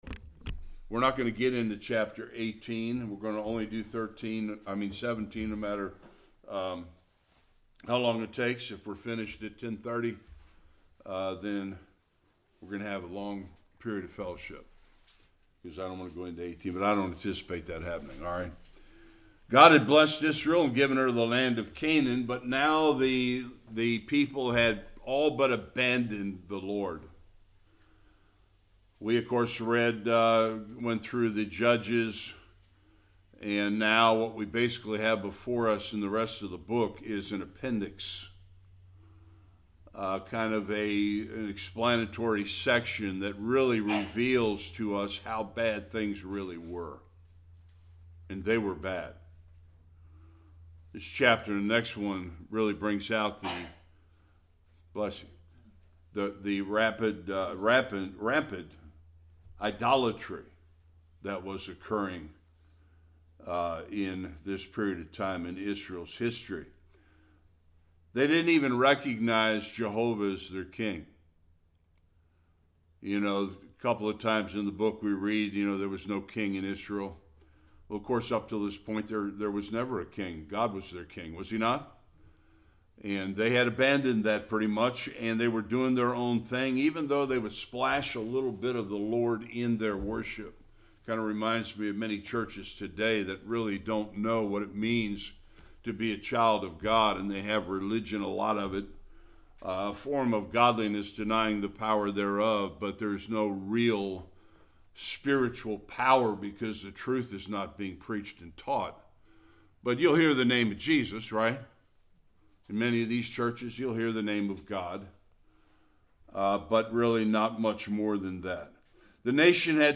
1-13 Service Type: Sunday School Idolatry and true religion don’t mix.